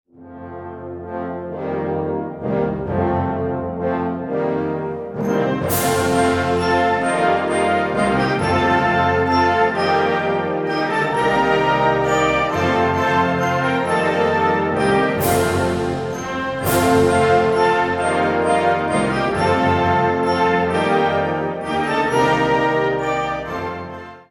Categorie Harmonie/Fanfare/Brass-orkest
Subcategorie Suite
Bezetting Ha (harmonieorkest)